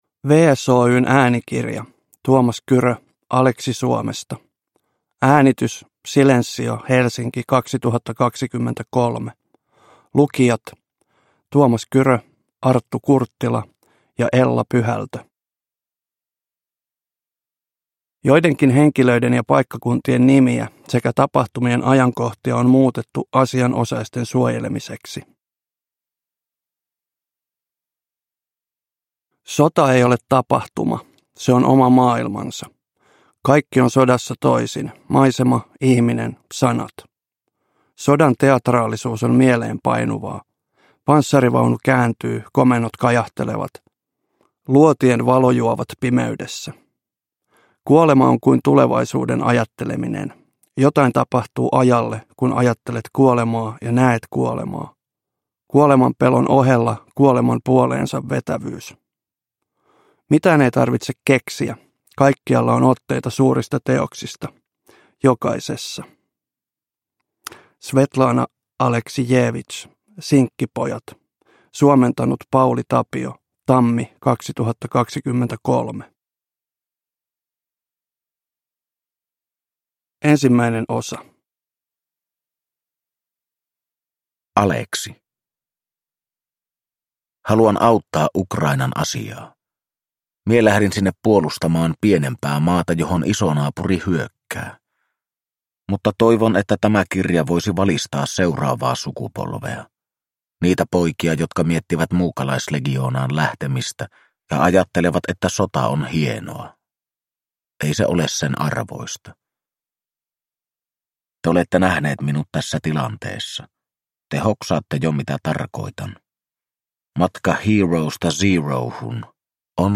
Aleksi Suomesta – Ljudbok – Laddas ner